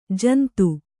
♪ jantu